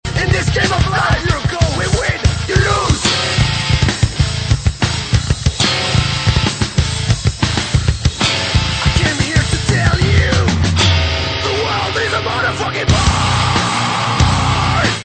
des musiques auto-produites
hardcore